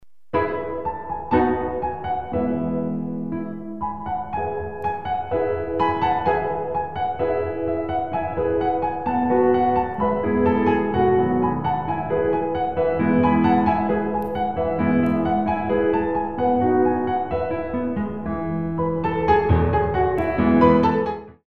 Piano Arrangements of Classical Compositions